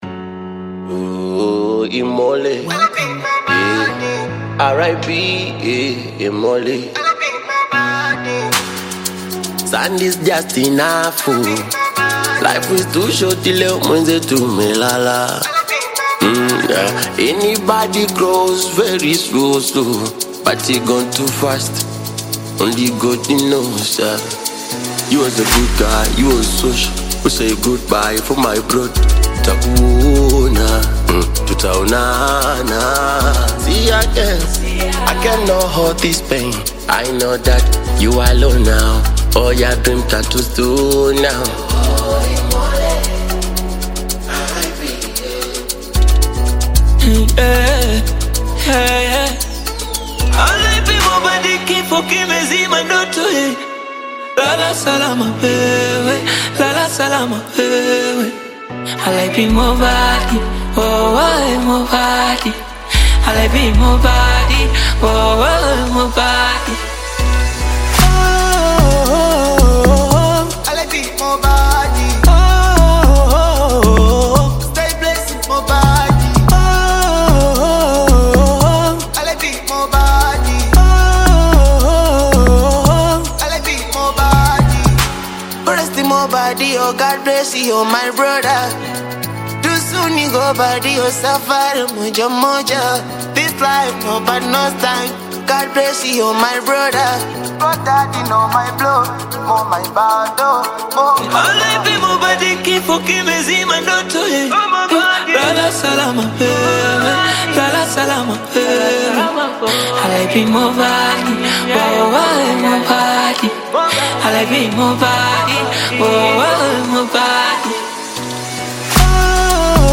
Tanzanian Bongo Flava Duo
a bittersweet tribute